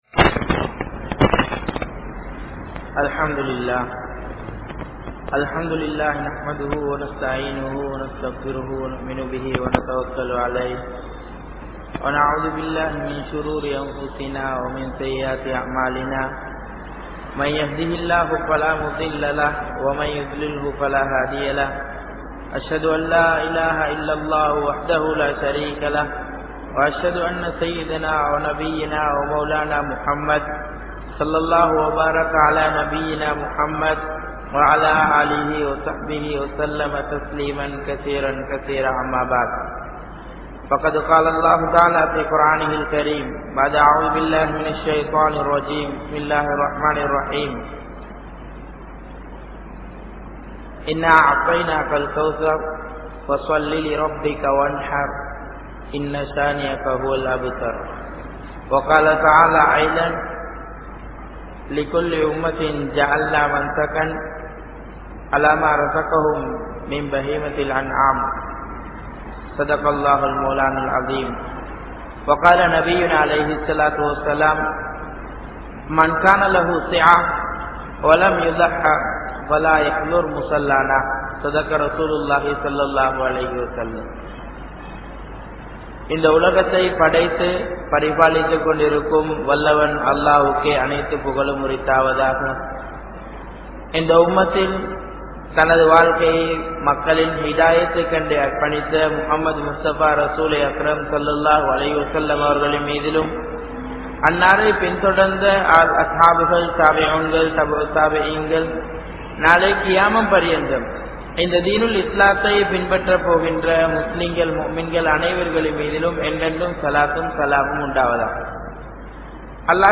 Vaalkaien Noakkam (வாழ்க்கையின் நோக்கம்) | Audio Bayans | All Ceylon Muslim Youth Community | Addalaichenai